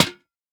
Minecraft Version Minecraft Version 1.21.5 Latest Release | Latest Snapshot 1.21.5 / assets / minecraft / sounds / block / lantern / break1.ogg Compare With Compare With Latest Release | Latest Snapshot